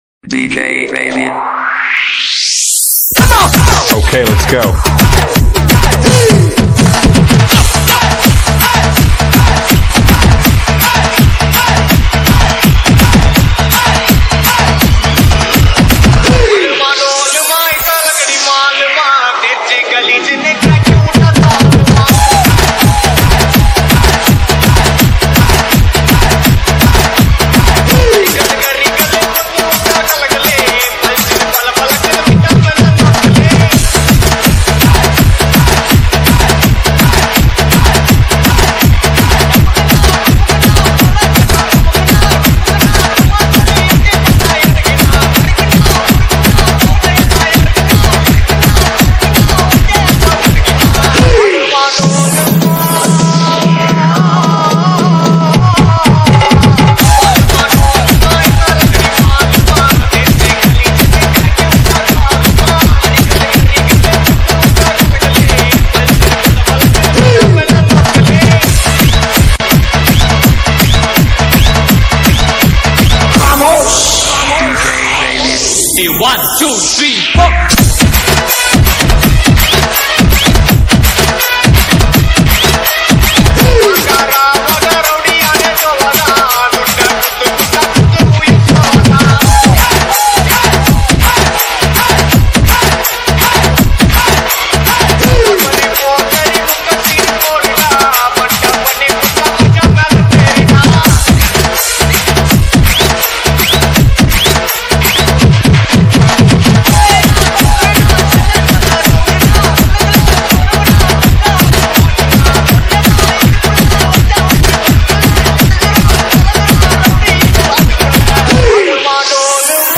ALL TAMIL LOVE DJ REMIX